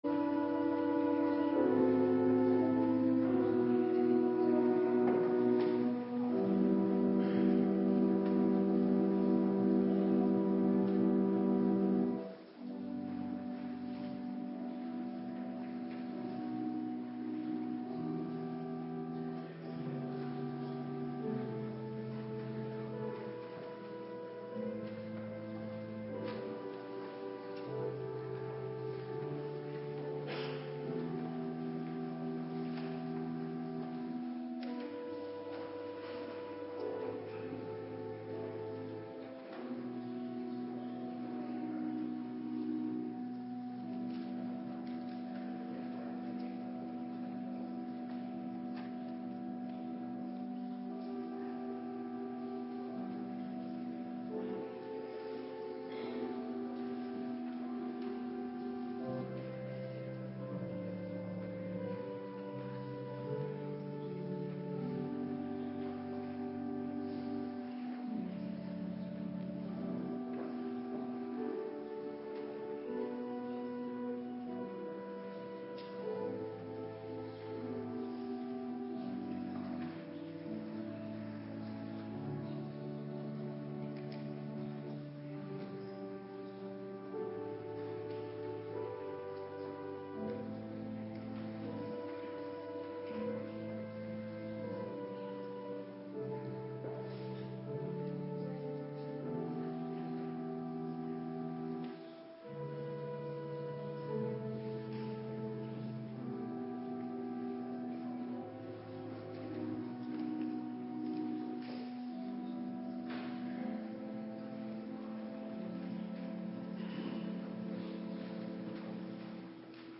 Morgendienst
Locatie: Hervormde Gemeente Waarder